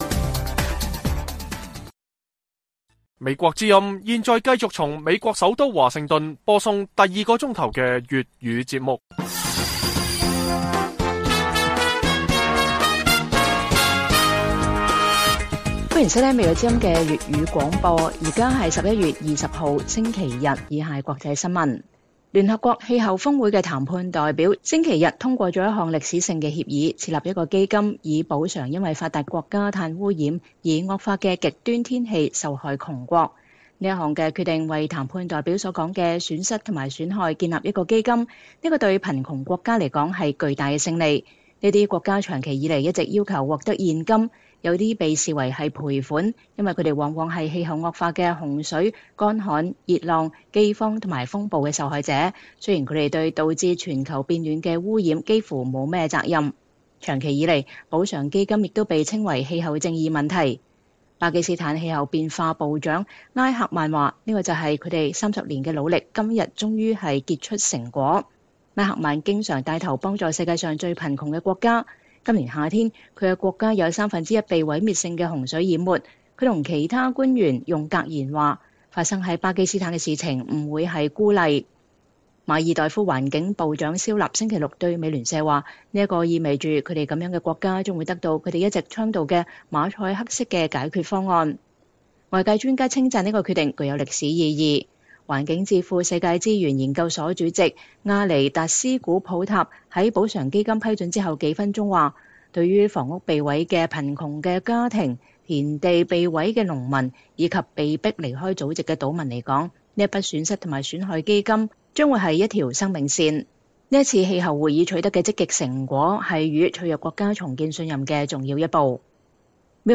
粵語新聞 晚上10-11點
北京時間每晚10－11點 (1400-1500 UTC)粵語廣播節目。內容包括國際新聞、時事經緯和英語教學。